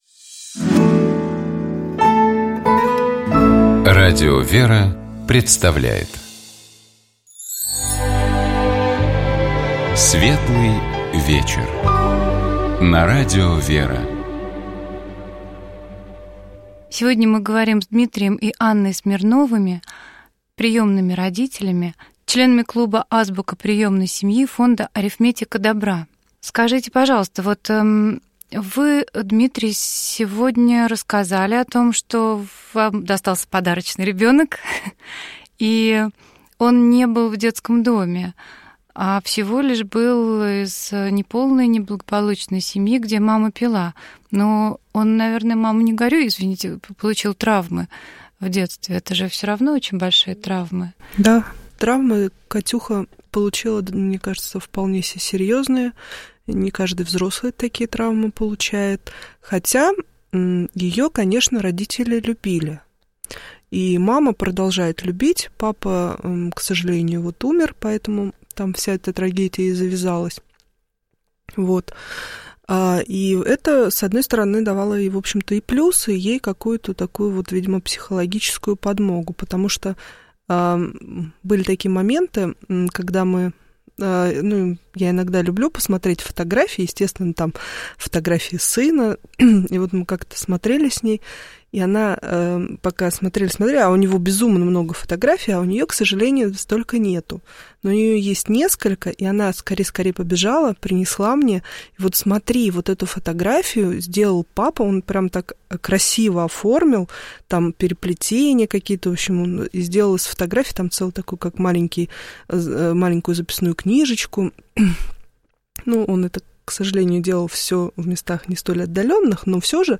Мы говорим, как всегда, о семье.